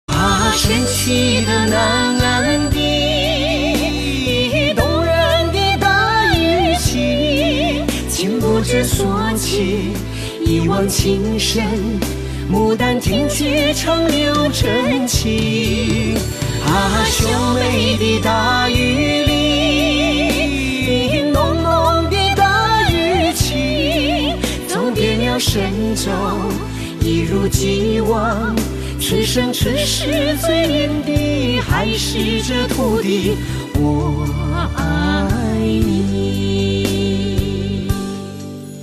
华语歌曲